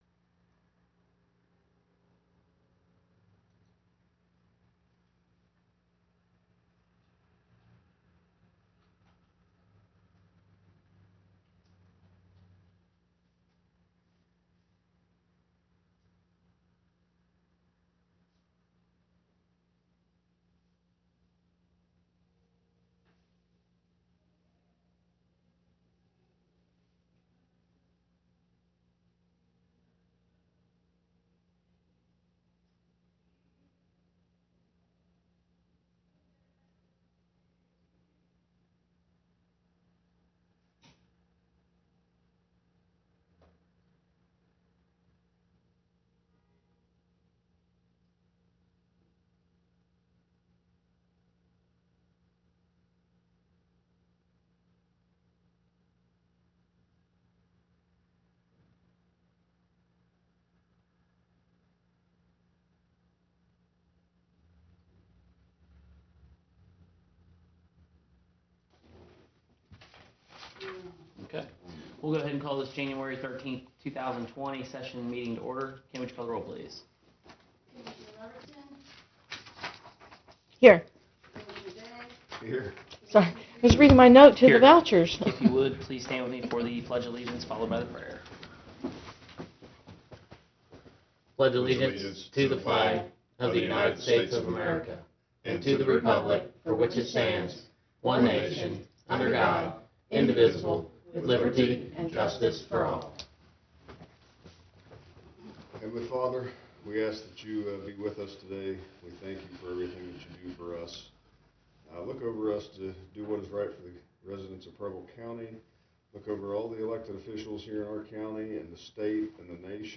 This is the audio review for the Monday, January 13, 2020, Preble County Commission meeting.
Preble County Commissioners January 13, 2020 Three Commissioners in attendance Begins just after 1 minute mark on cd.
Extended pause as no one nominates anyone.
PUBLIC COMMENTS 9.25 minute mark Could not make out his name.